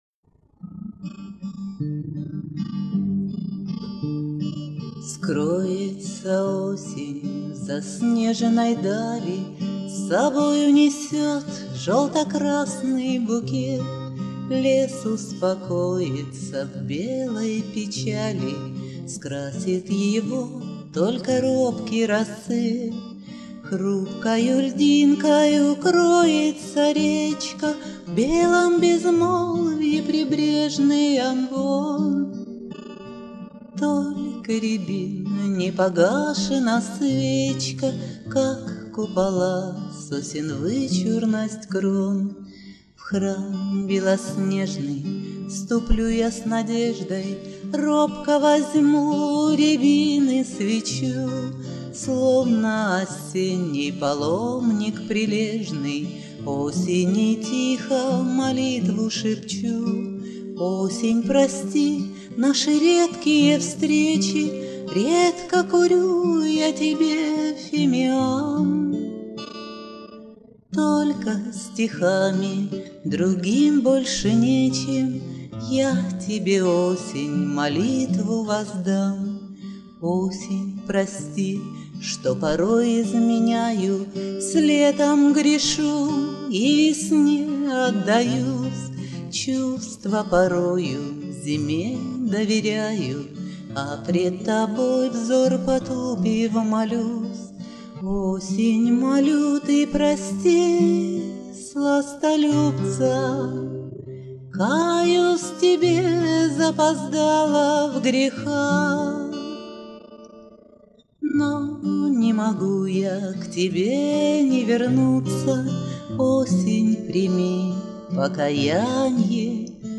Шансон
Записала их по-памяти в домашних условиях.